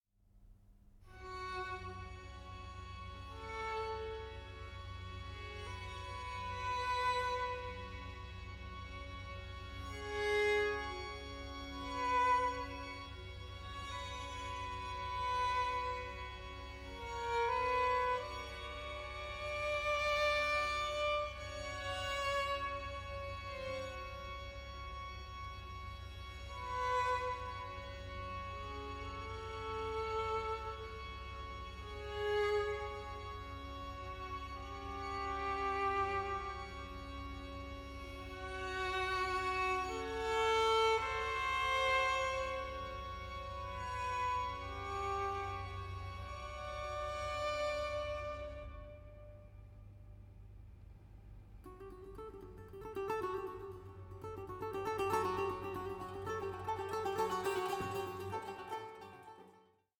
BAROQUE MEETS THE ORIENT: MUSIC AS INTERCULTURAL DIALOGUE